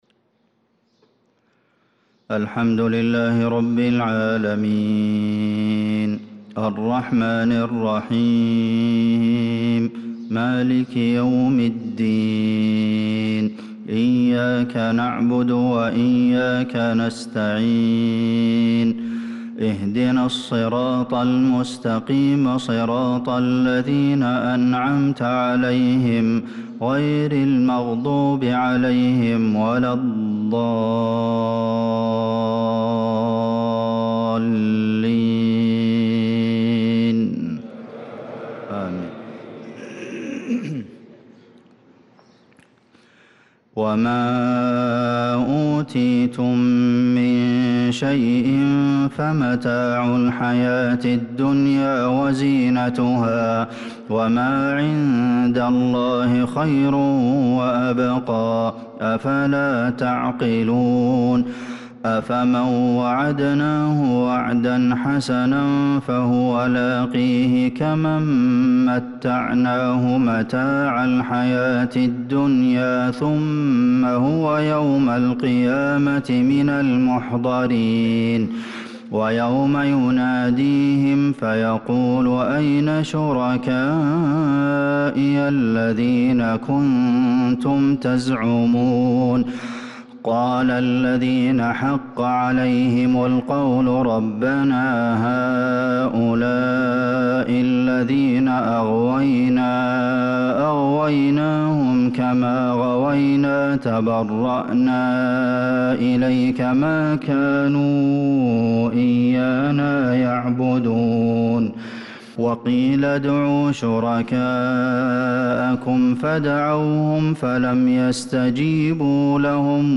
صلاة الفجر للقارئ عبدالمحسن القاسم 27 شوال 1445 هـ